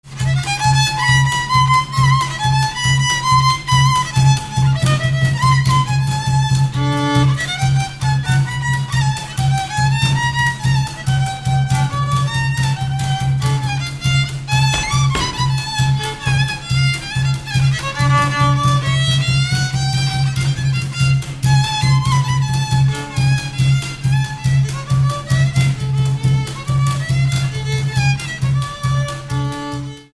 Dallampélda: Hangszeres felvétel
Erdély - Csík vm. - Csíkszentdomokos
hegedű
ütőgardon
Műfaj: Lassú csárdás
Stílus: 4. Sirató stílusú dallamok